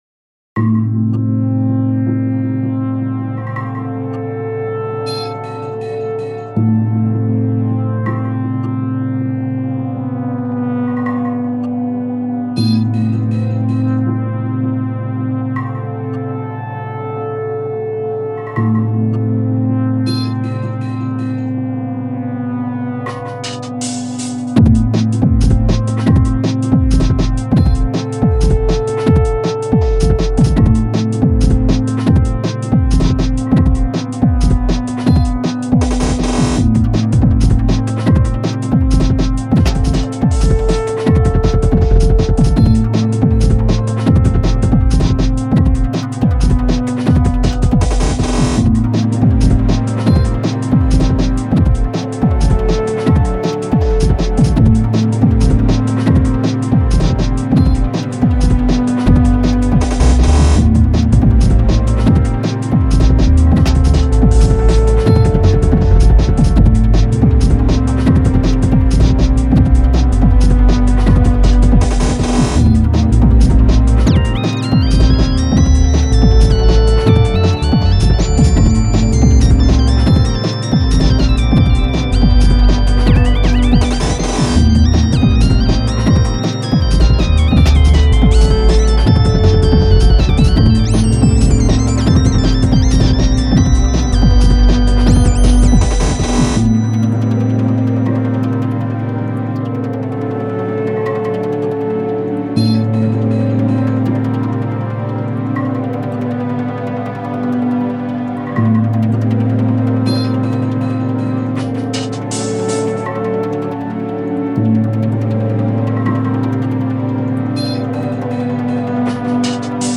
keyword/hint * Drum'n Bass / 2:12(short.ver) - mp3